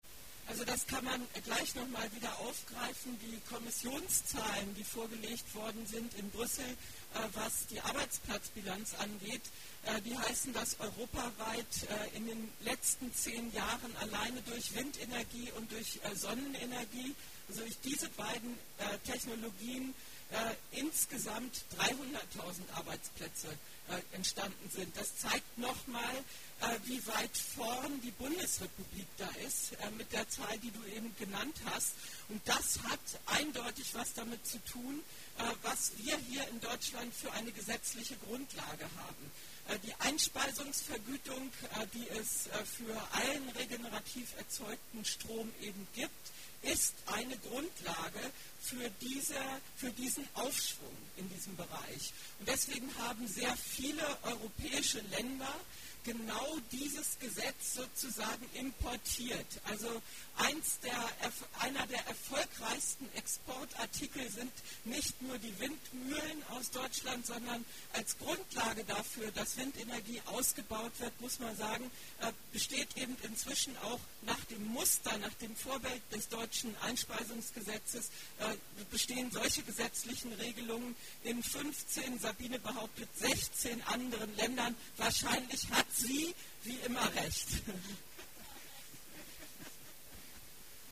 Solarwärme-Infotour in Dannenberg
Hier gibt es O-Ton zum herunterladen: